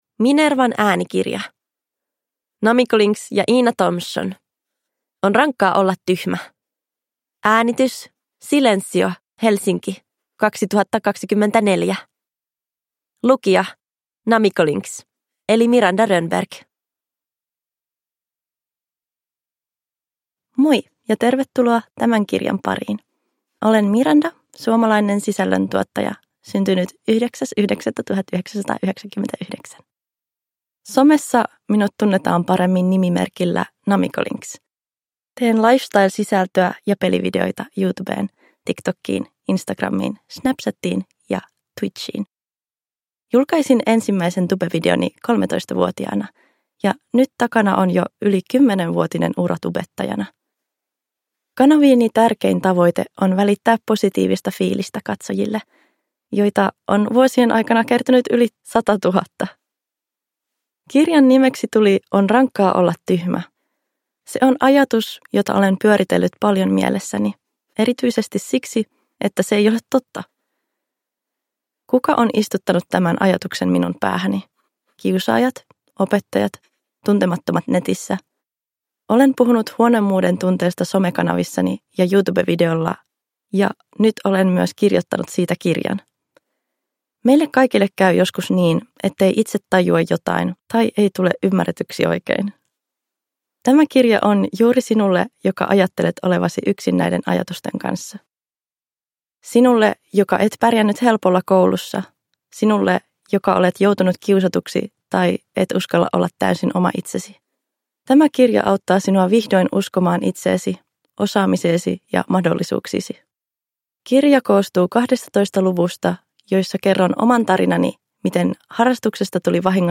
On rankkaa olla tyhmä – Ljudbok